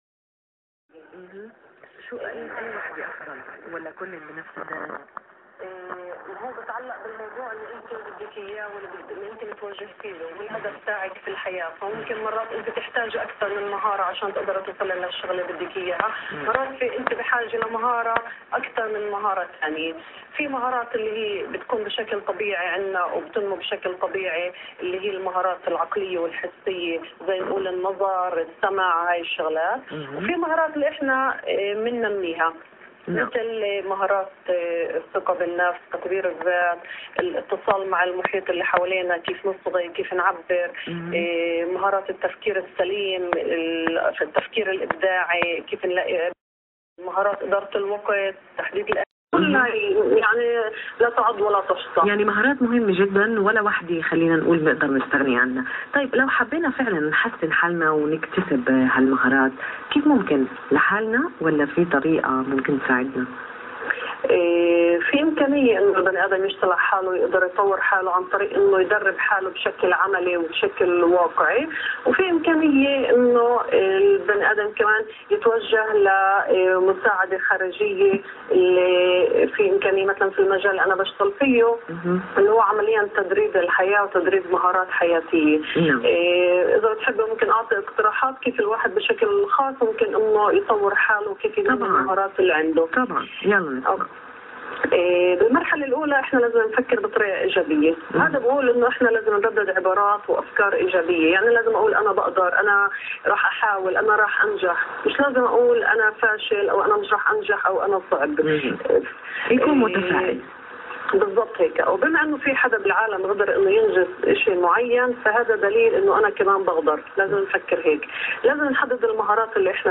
مقابلة راديو اسرائيل-مهارات حياتيه.mp3